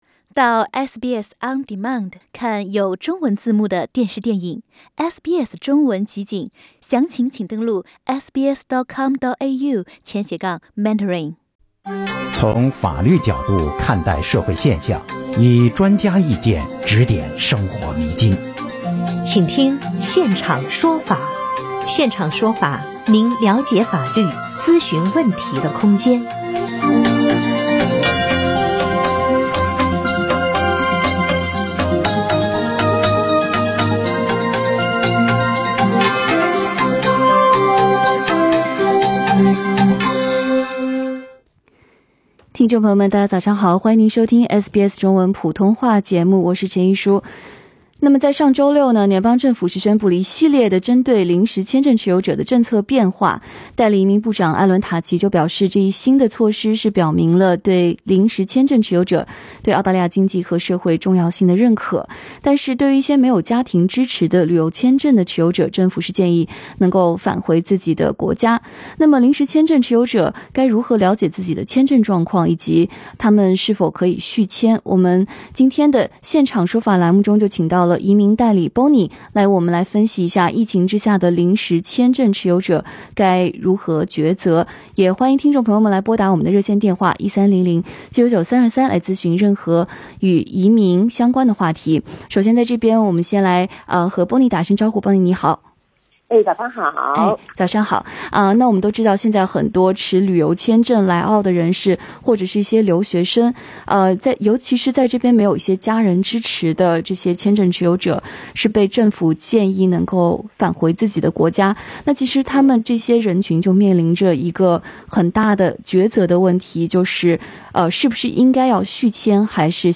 新冠疫情下，临时签证持有者该如何了解自己签证的状况？是否可以续签？SBS热线节目诸多问题，也许正是你也想咨询的。